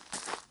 Rock Foot Step 3.wav